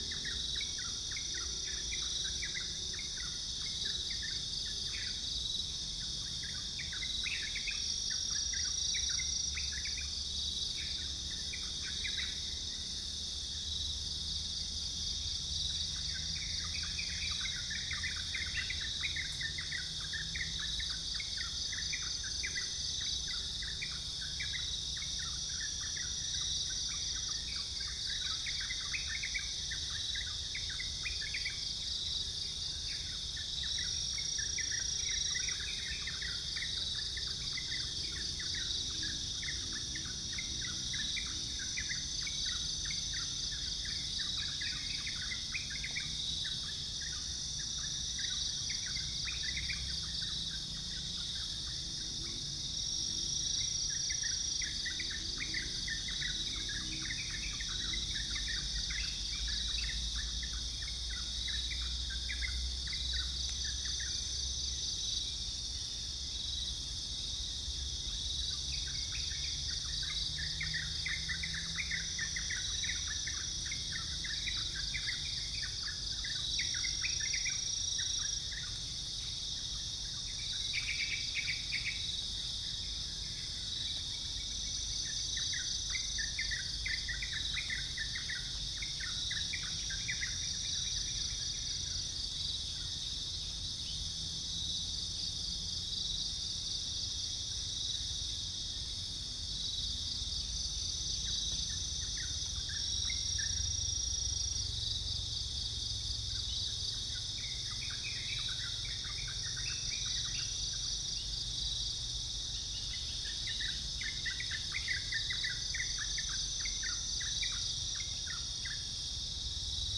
Spilopelia chinensis
Geopelia striata
Halcyon smyrnensis
Pycnonotus goiavier
Trichastoma bicolor
biophony
Dicaeum trigonostigma